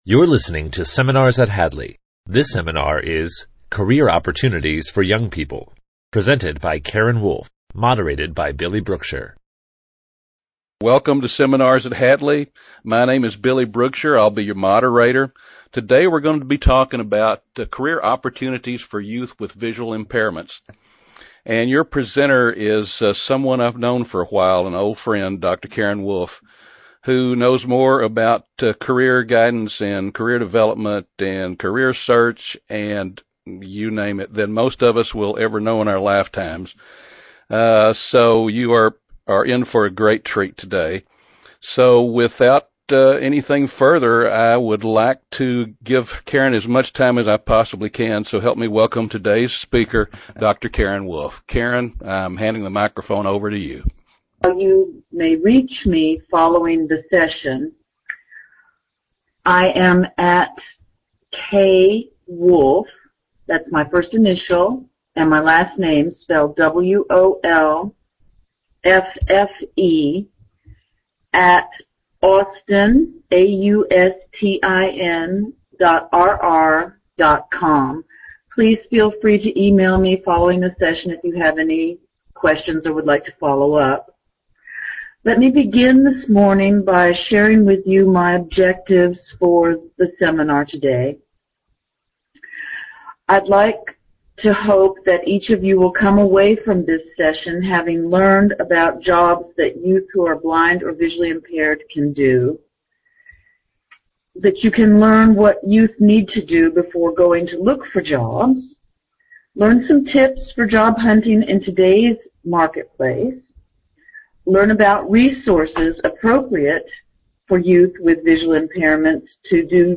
Career Opportunities for Youth with Vision Loss: Audio Seminar